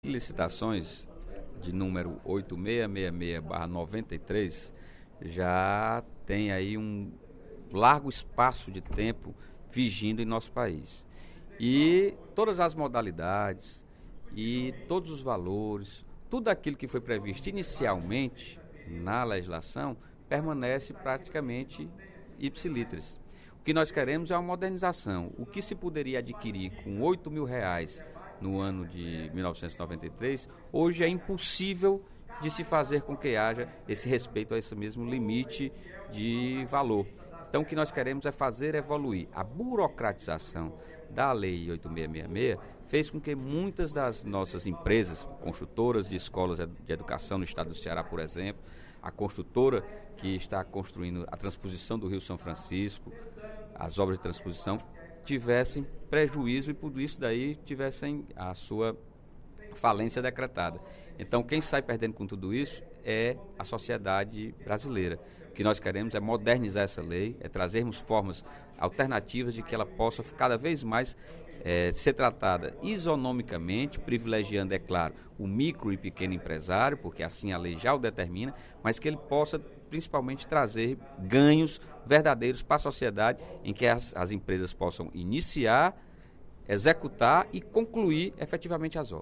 O deputado Sérgio Aguiar (PDT) fez, durante o primeiro expediente da sessão plenária desta sexta-feira (10/03), a defesa da aprovação do PLS 559, de 2013, que modifica a Lei 8.666, das licitações e contratos no País.